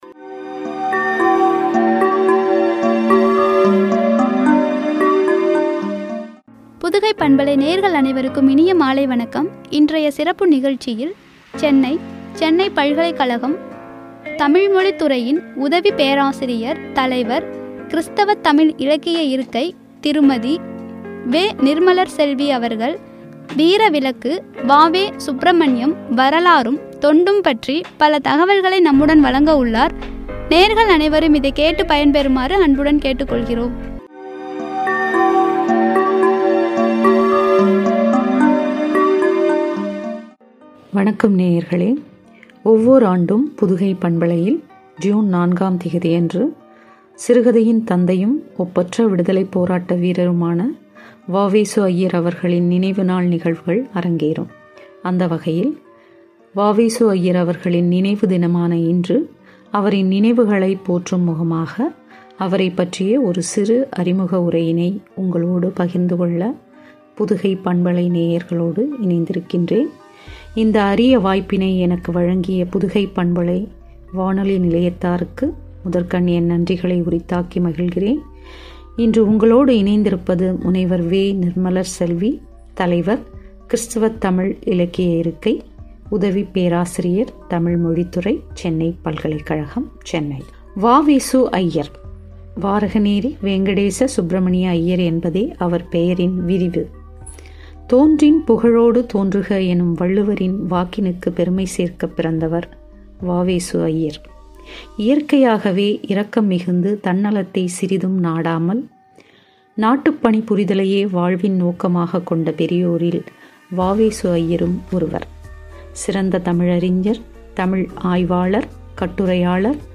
சுப்பிரமணியம் வரலாறும், தொண்டும்” குறித்து வழங்கிய உரையாடல்.